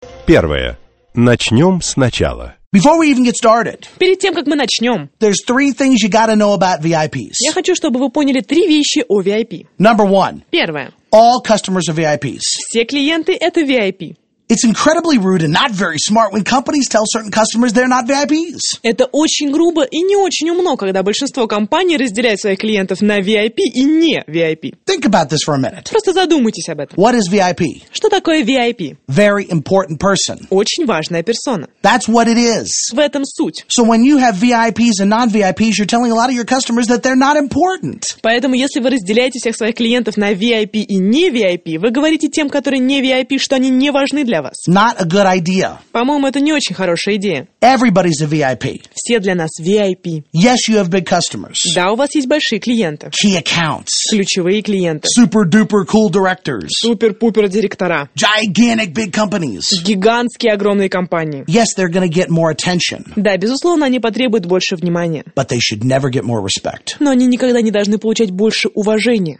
Аудиокнига Face Control